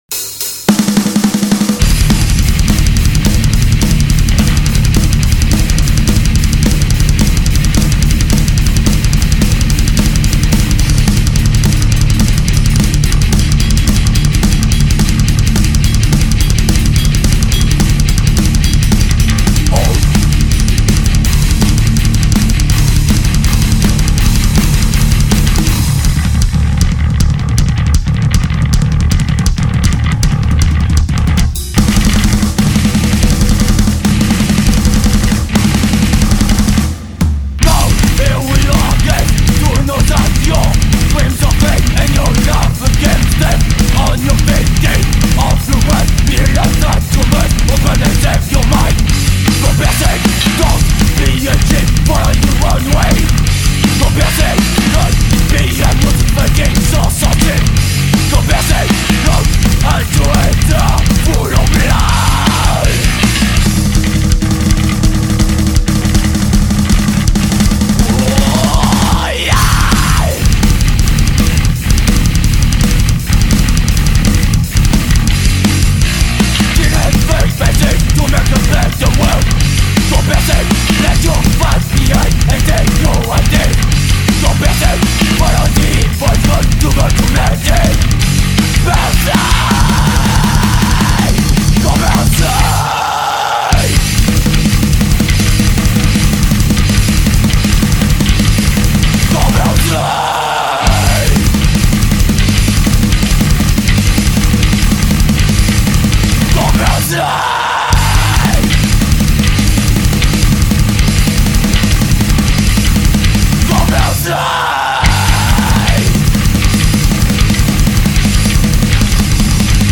thrash/death/power
batterie
basse
guitare
chant lead